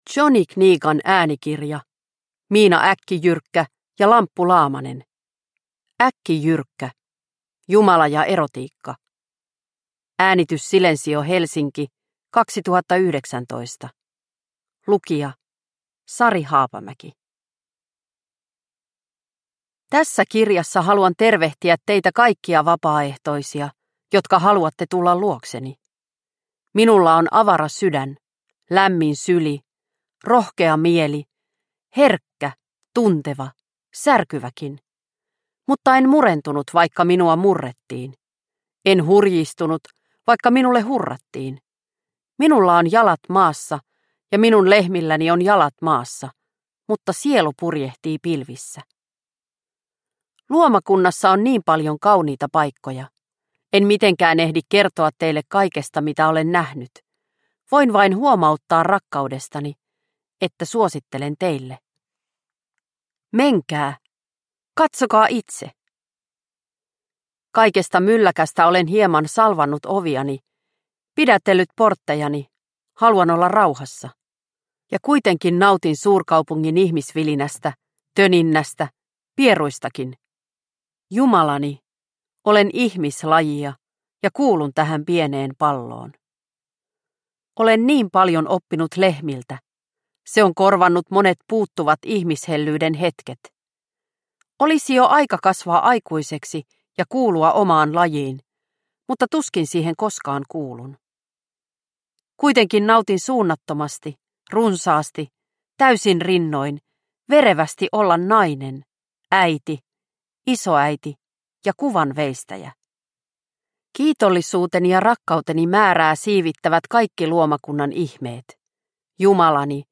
Äkkijyrkkä – Ljudbok – Laddas ner